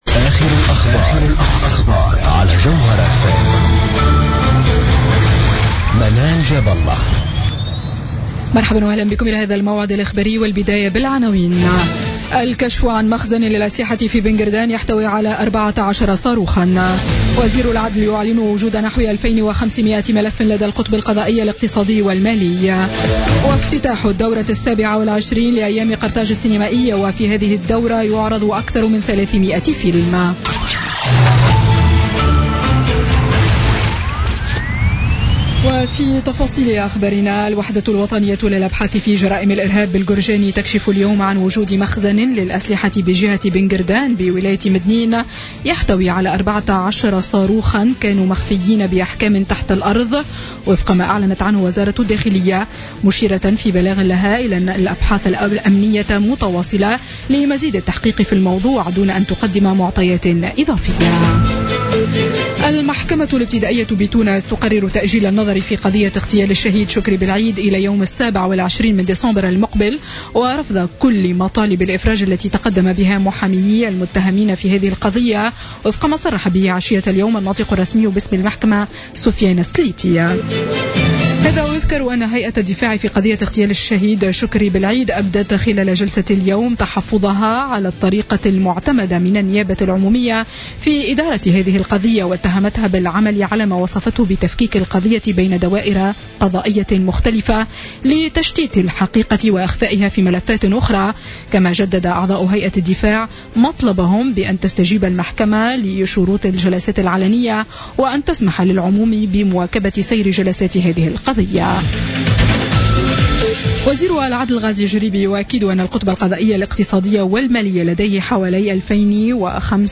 نشرة أخبار السابعة مساء ليوم الجمعة 28 أكتوبر 2016